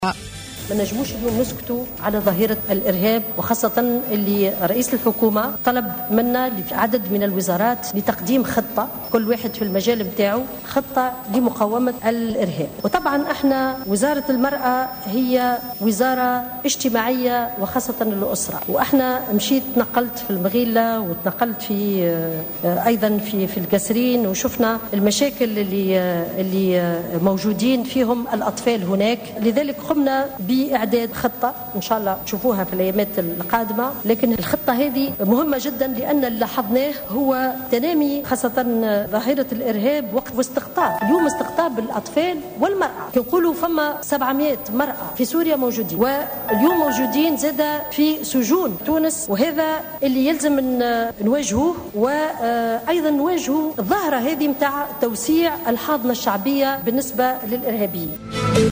نشرة أخبار السابعة مساء ليوم الجمعة 04 ديسمبر2015